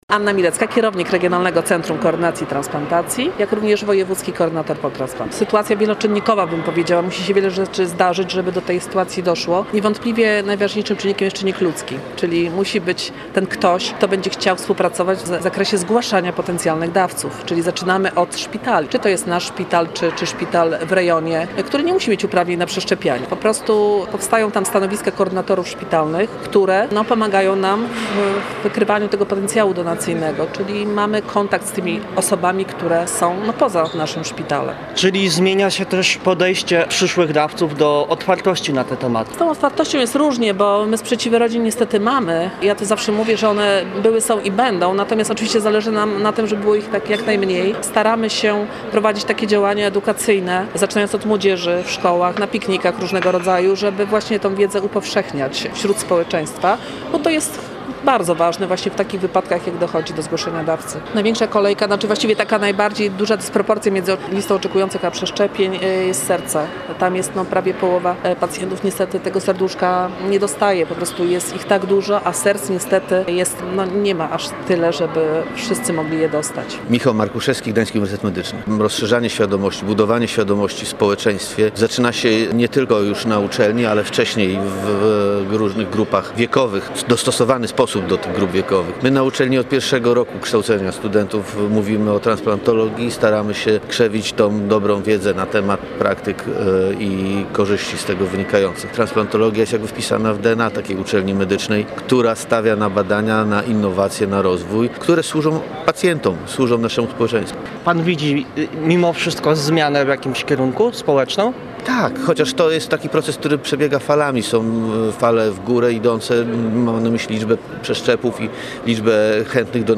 Podczas Gali Transplantologii eksperci wymieniali także doświadczenia i rozmawiali o wyzwaniach. Posłuchaj materiału naszego reportera: https